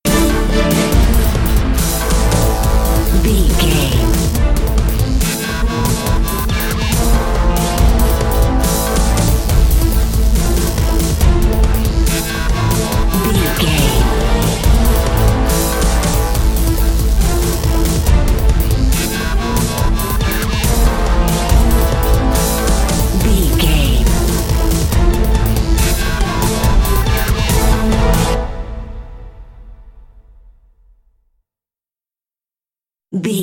Fast paced
In-crescendo
Aeolian/Minor
strings
drums
dubstep
synth effects
driving drum beat